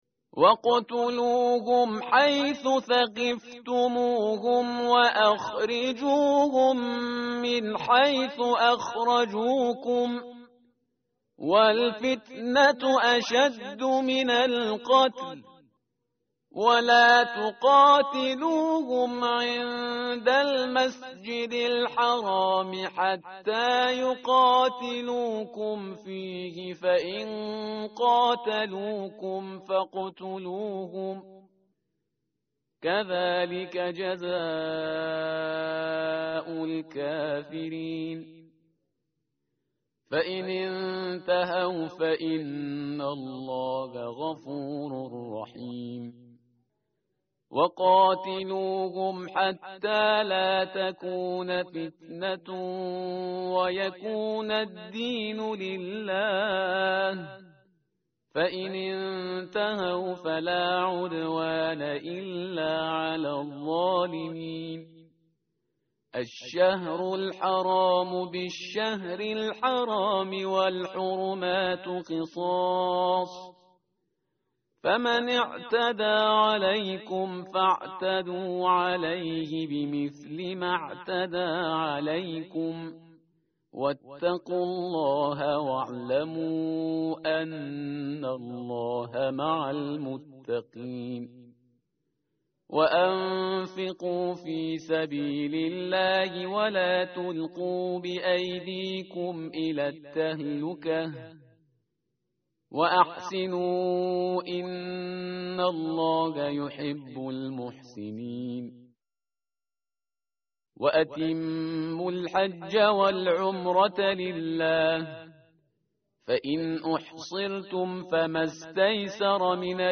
متن قرآن همراه باتلاوت قرآن و ترجمه
tartil_parhizgar_page_030.mp3